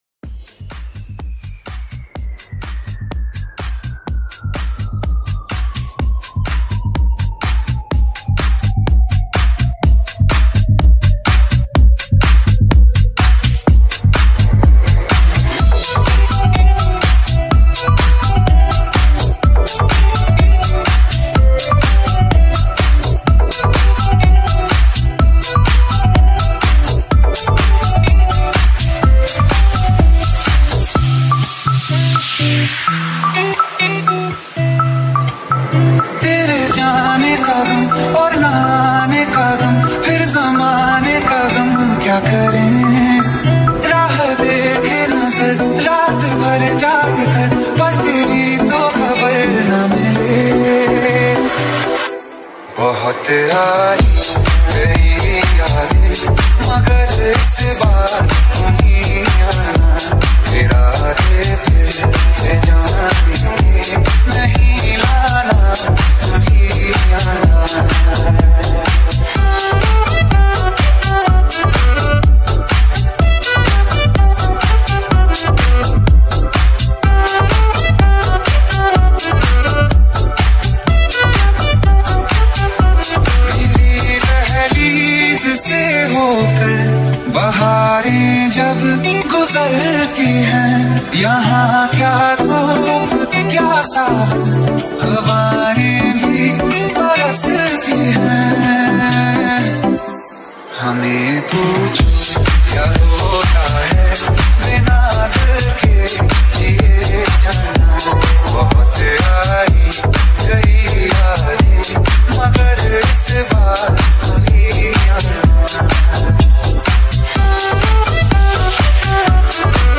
Genre - Tech House
BPM - 125